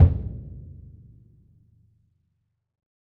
BDrumNewhit_v4_rr1_Sum.wav